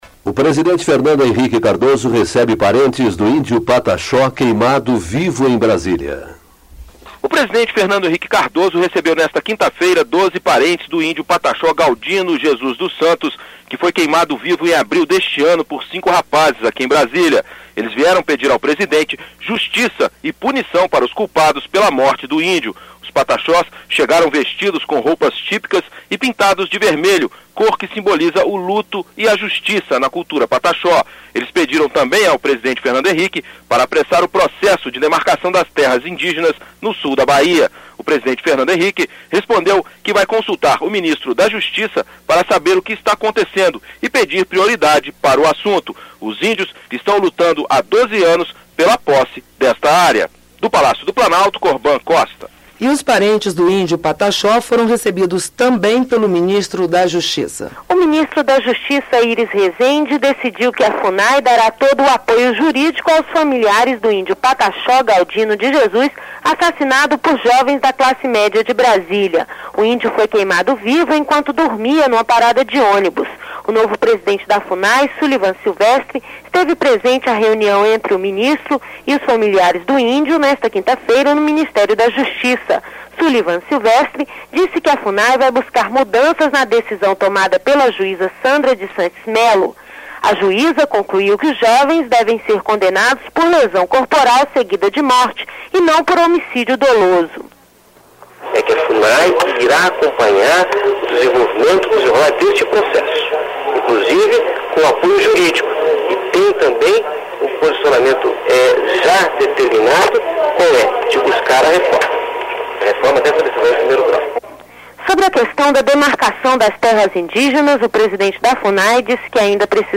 Reportagem do programa A Voz do Brasil: